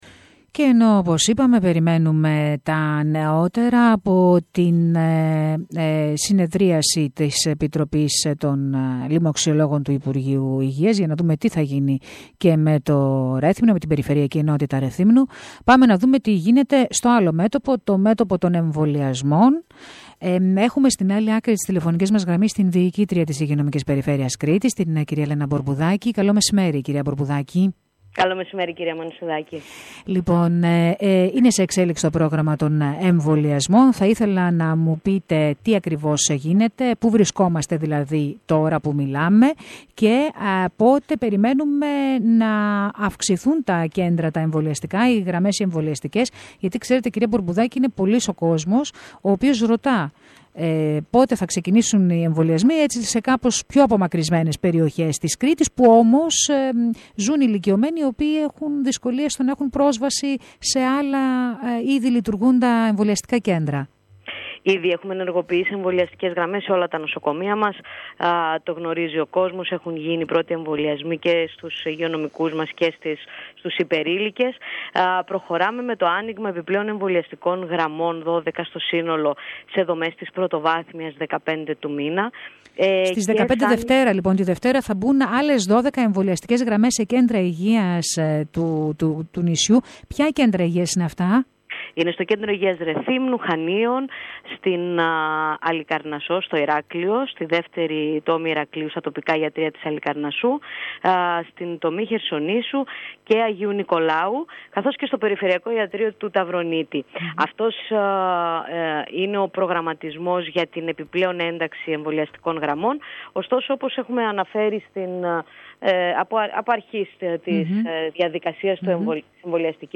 Ακολουθεί η συνέντευξη της διοικήτριας της 7ης ΥΠΕ, Λένας Μπορμπουδάκη, στην ΕΡΤ Ηρακλείου